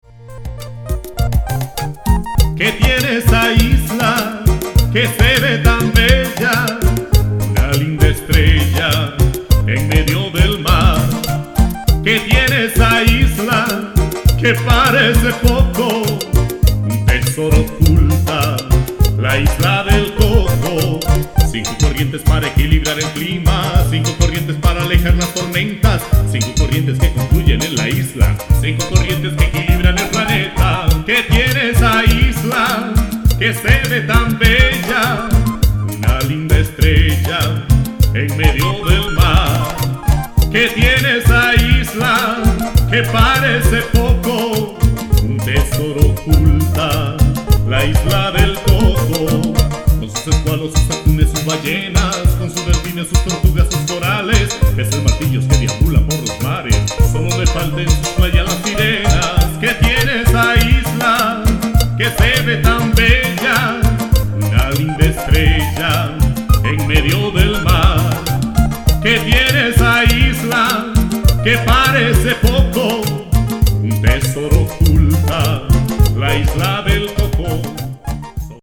Fue compuesta especialmente para el espectáculo.